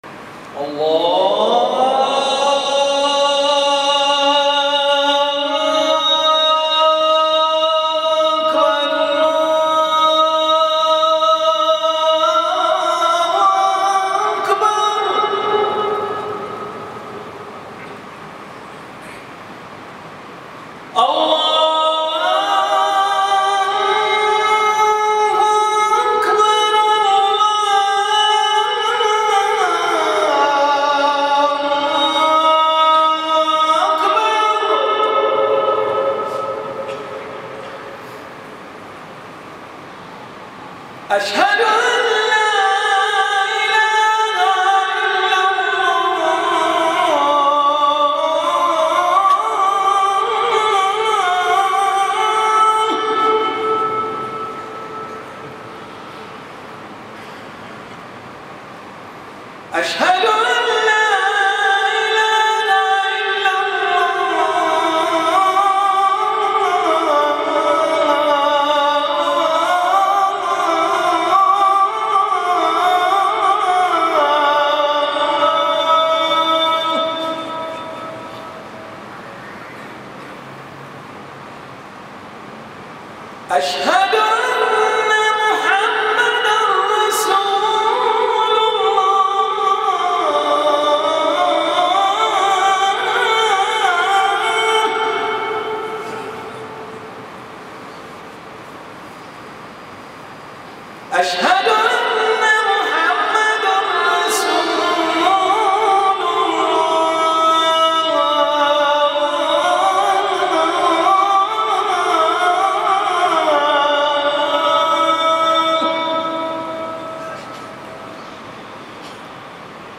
Download Beautiful Adhan by Sheikh Ali Ahmed Mulla